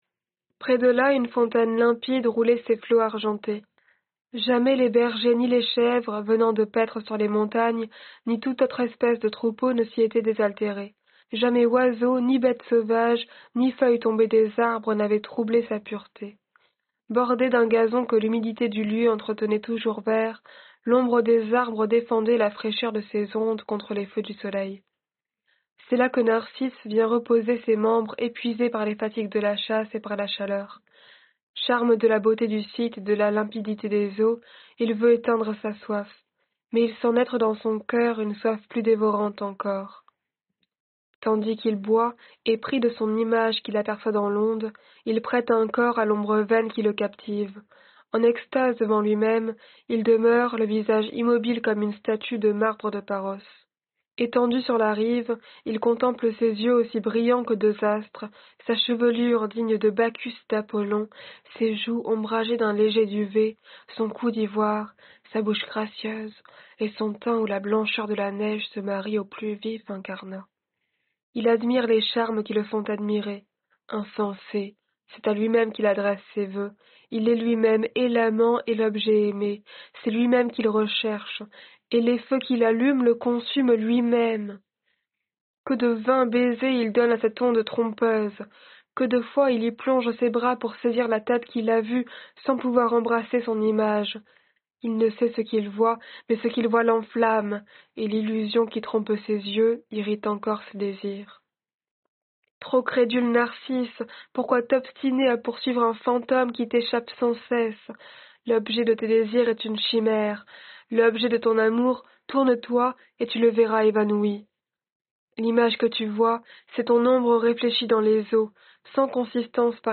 Lecture de la métamorphose de Narcisse · GPC Groupe 1